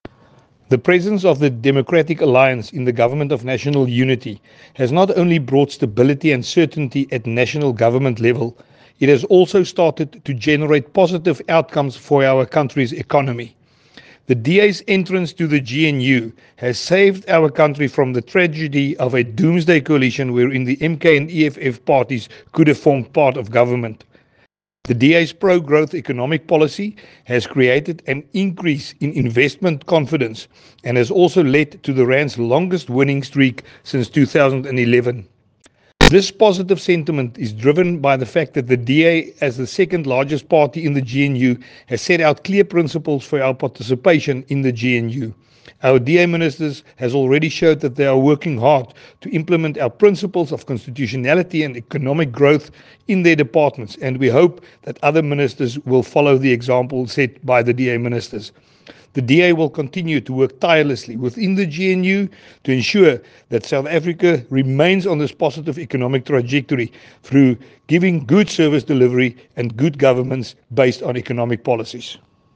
Issued by Willie Aucamp MP – DA Spokesperson
Note to editors: Please find attached soundbites in